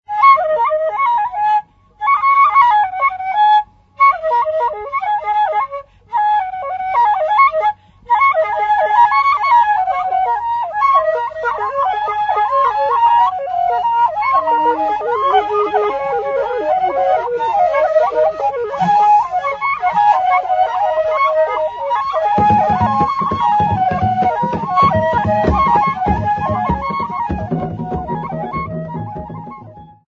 Abalere ba Kabaka (Performer)
Ndere end-blown flute
Cylindrical drum
Laced conical drum
Topical song by Abalere ba Kabaka using ndere end-blown flute, accompanied by cylindrical and laced conical drum
Original format: 15ips reel
Hugh Tracey (Recorded by)